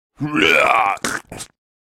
owl-puke.ogg.mp3